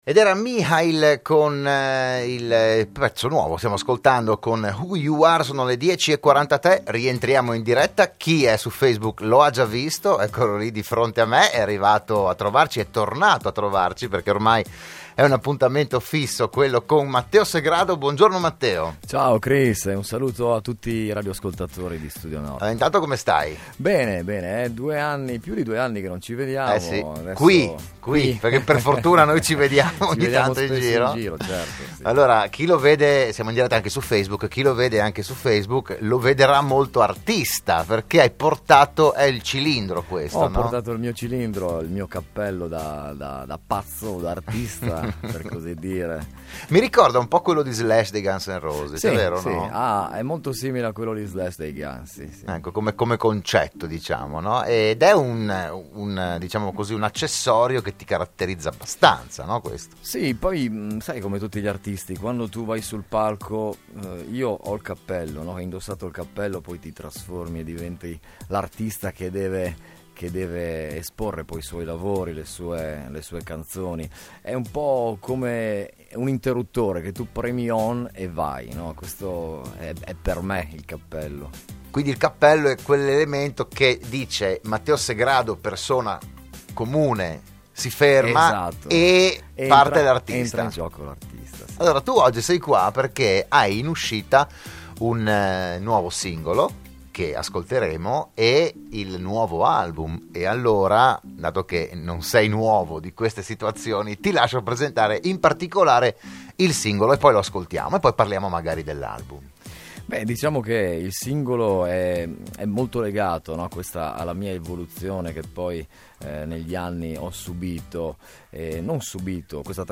Il PODCAST e il VIDEO dell'intervento del musicista carnico a "RadioAttiva"